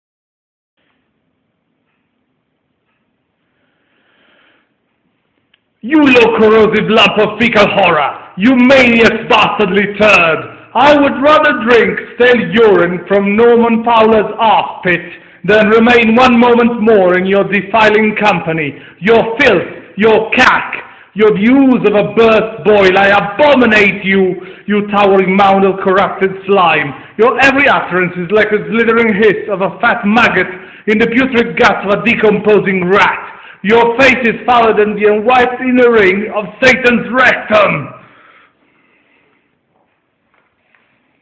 I tried to 'perform' this bit from A Bit of Fry and Laurie (I guess).